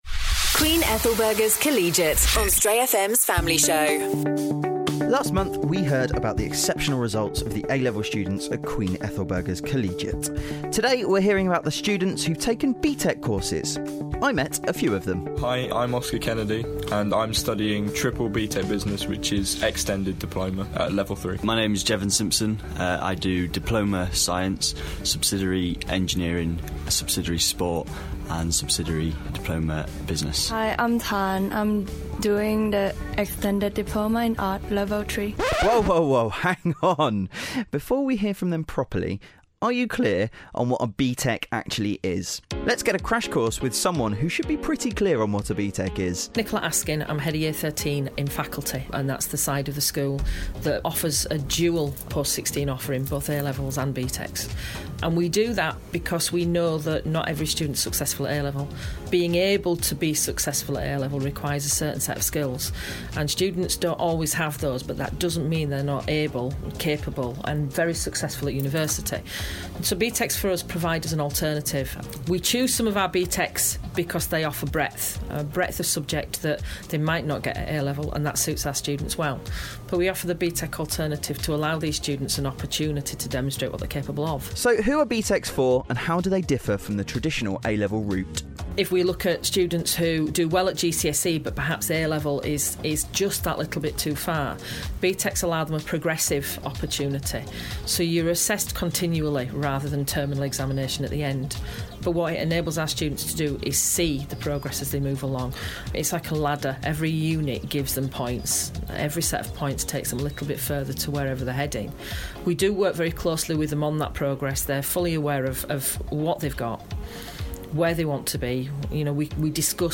But, what about BTECs? The team from Queen Ethelburga's Faculty explain these vocational qualifications and how they can lead to success for students who don't fit the A-Level mould.